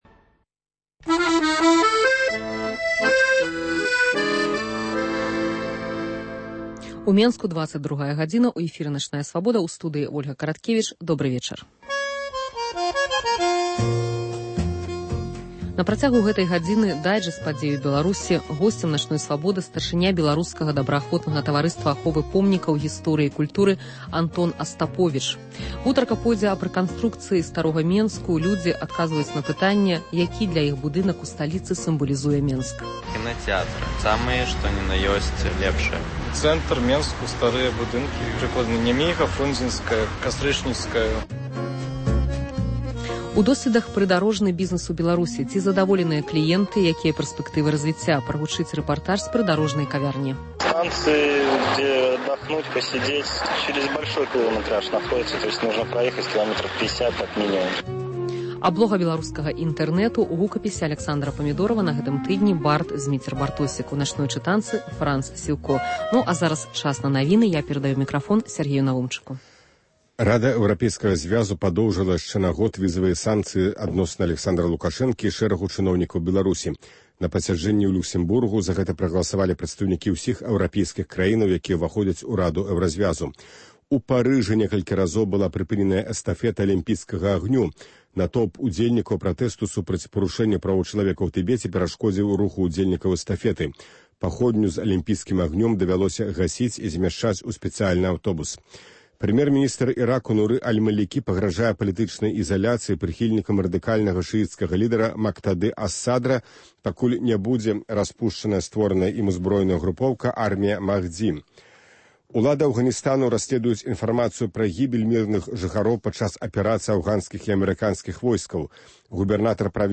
Прагучыць рэпартаж з прыдарожнай кавярні.